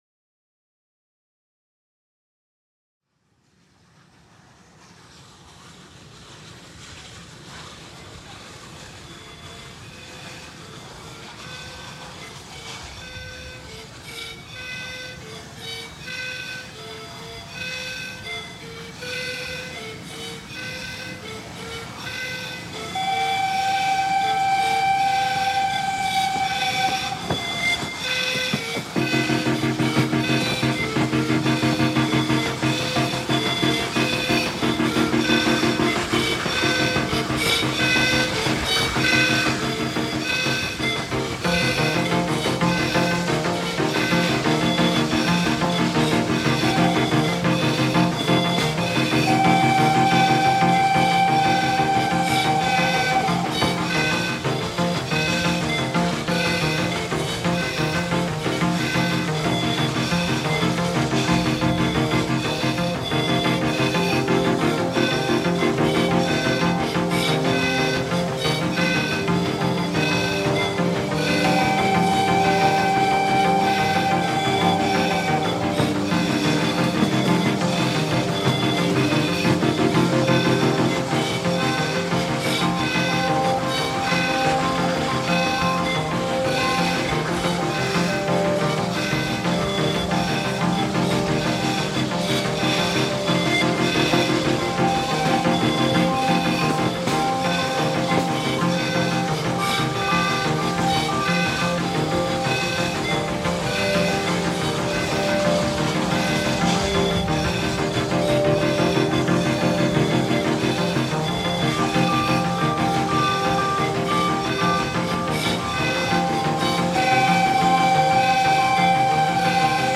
From the moment that I first heard this sound, I thought the flute sounded very much like a little steam train.
I wanted the listener to hear the original recording in the track but did edit the order, tempo and pitch to give an idea of the effort of the train on its journey. I then added a rhythmic synth line to give a feeling of travel and sourced some steam train sounds from freesound. Towards the end of the track I layered other synth lines and a church organ as the train reaches its destination, the salt cathedral.